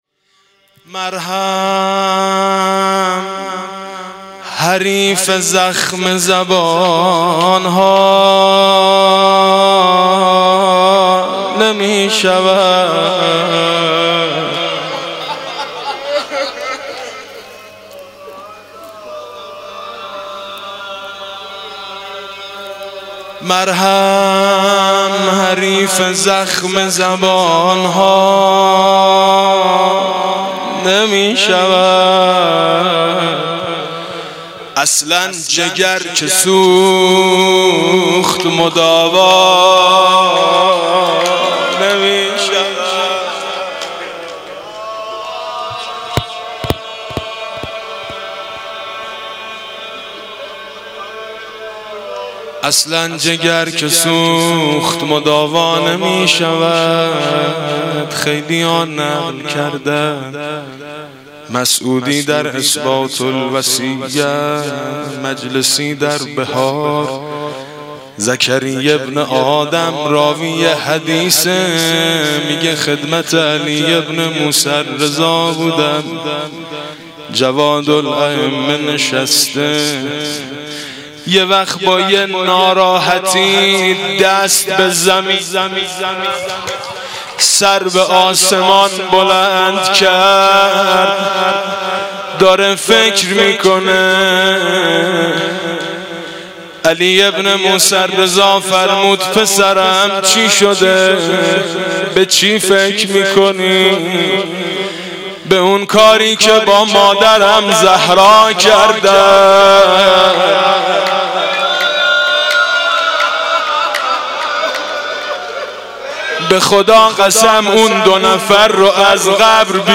«شهادت امام جواد 1390» روضه: مرهم حریف زخم زبان ها نمی شود